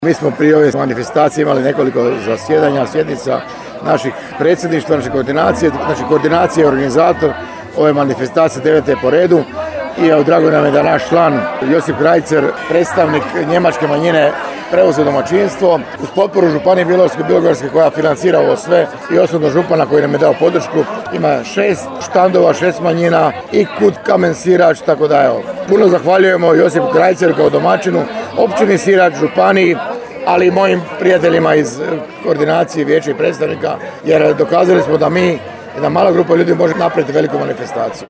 U subotu je upriličena 9. Smotra manjinskog stvaralaštva.